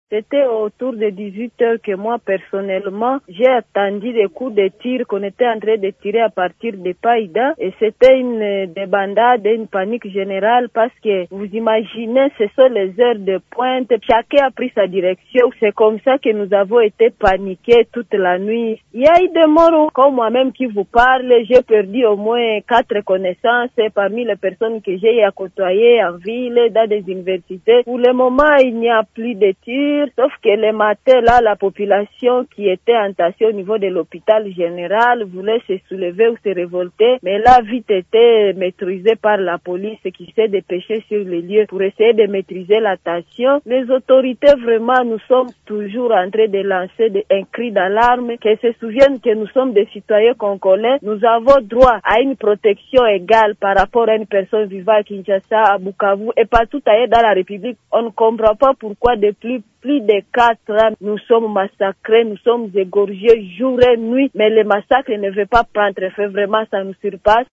Pour cette femme qui témoigne, les choses sont allées vite entre le moment de l’attaque et celui de se mettre à l’abri.